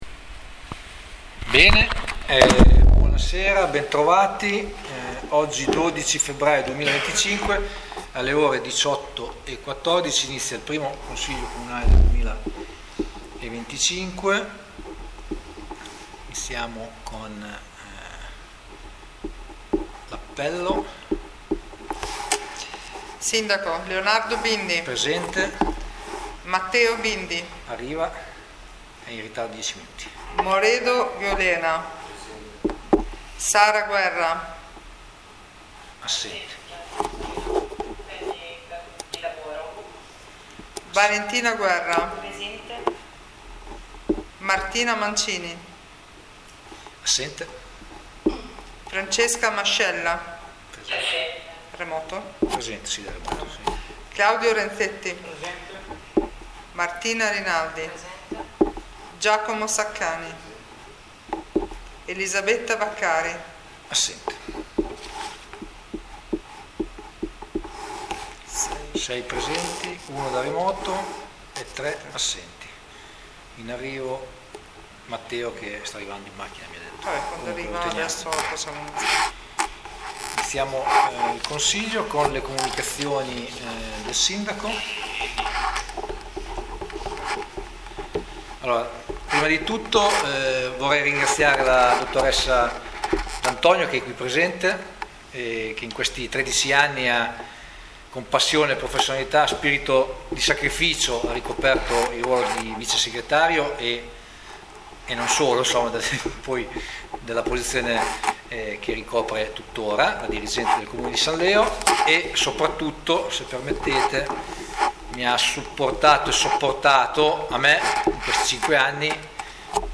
In questa pagina sono disponibili le registrazioni audio delle sedute del Consiglio Comunale.